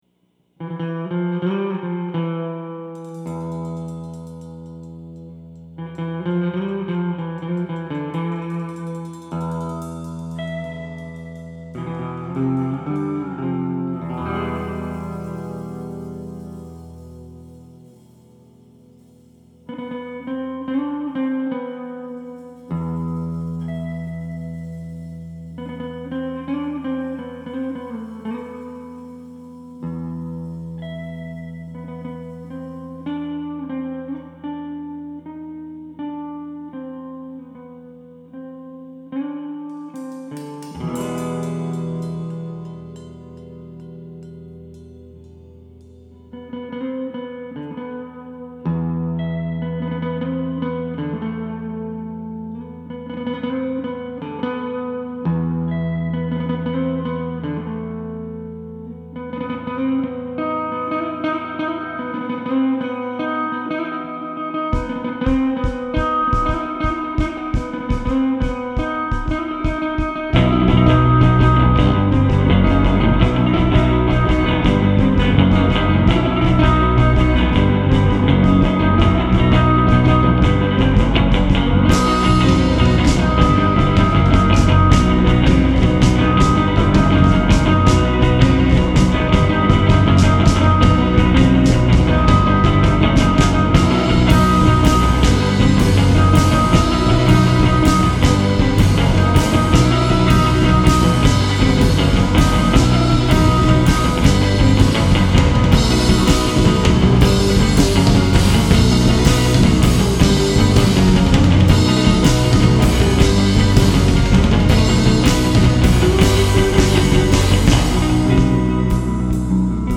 guitar.
drums.
bass.